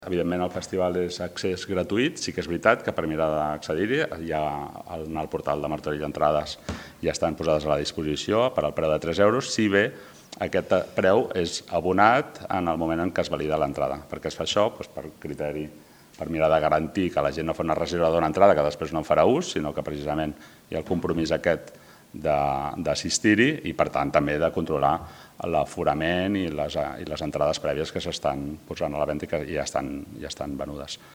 Presentació El Petit
Sergi Corral, regidor de Cultura